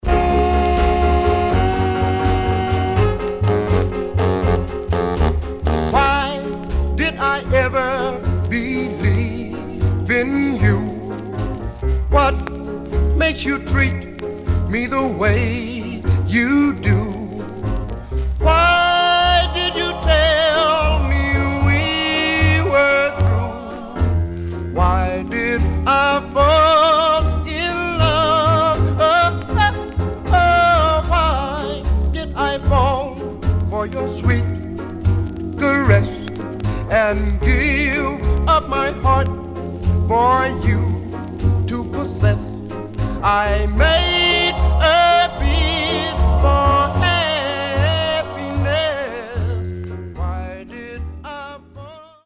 Mono
Male Black Group